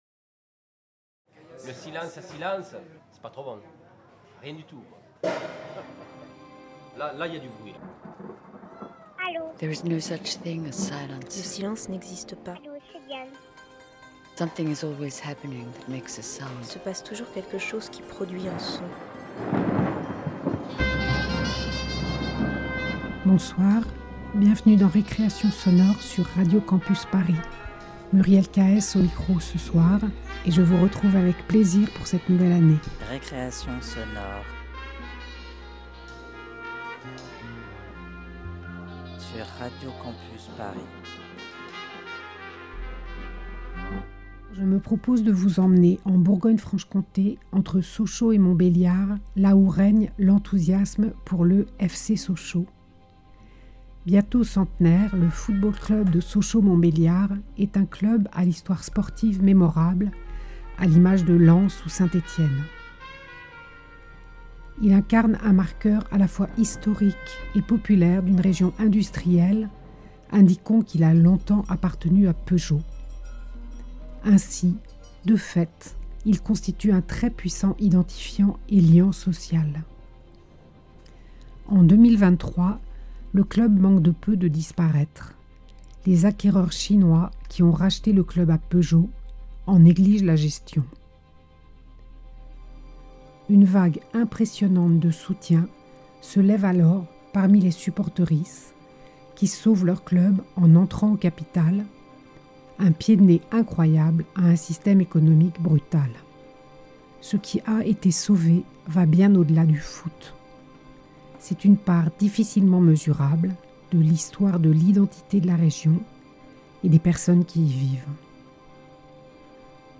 Récréation sonore - Radio Campus Paris